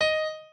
pianoadrib1_4.ogg